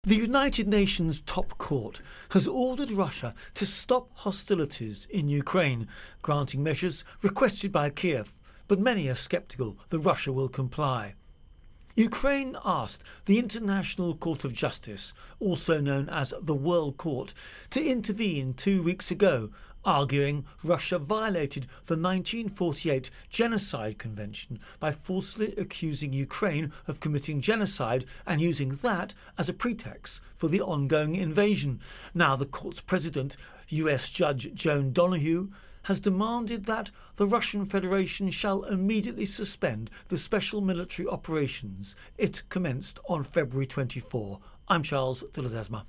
Russia Ukraine War World Court Intro and Voicer